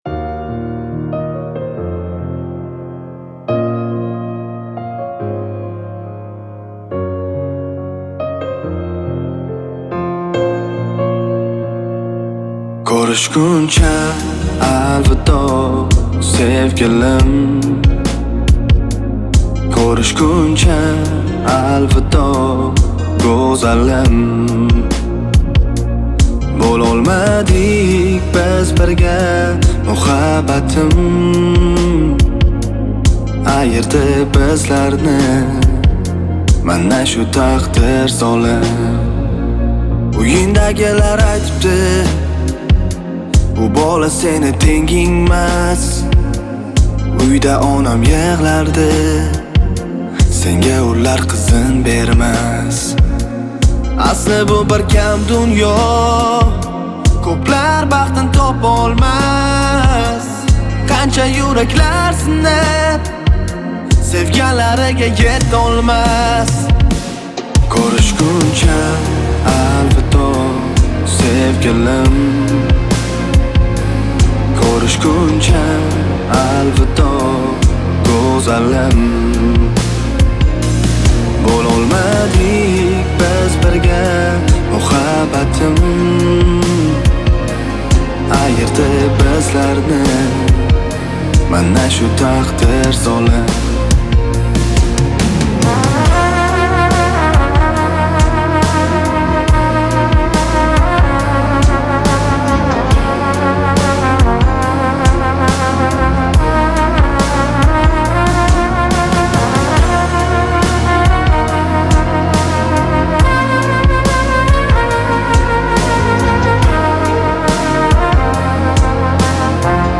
Жанр: Узбекские треки